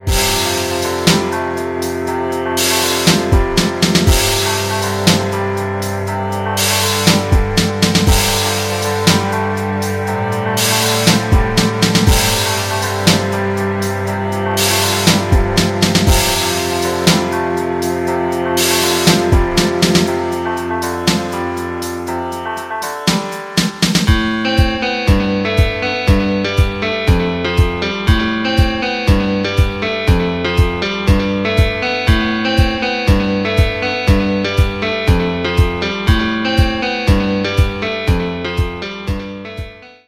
Нарезка на смс или будильник